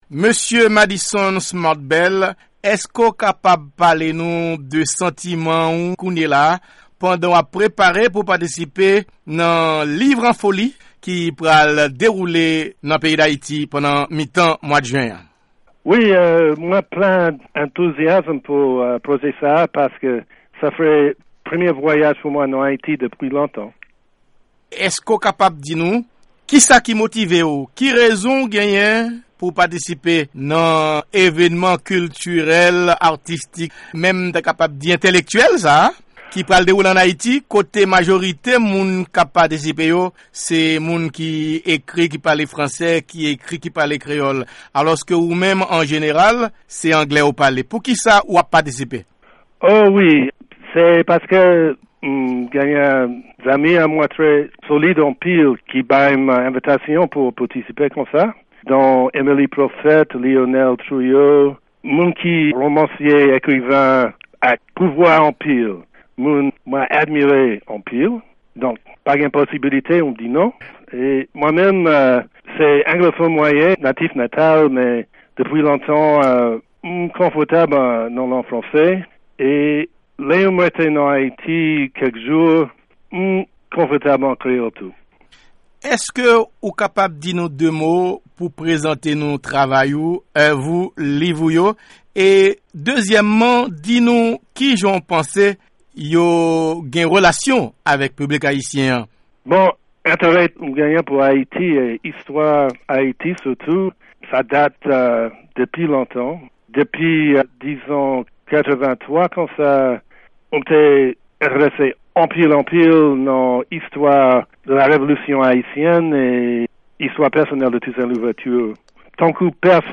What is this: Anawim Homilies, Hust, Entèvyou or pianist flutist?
Entèvyou